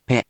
We have our computer friend, QUIZBO™, here to read each of the hiragana aloud to you.
#5.) Which hiragana do you hear? Hint: 【pe】
In romaji, 「ぺ」 is transliterated as 「pe」which sounds sort of like the English word「pay」without the ‘y’ sound or diphthong at the end.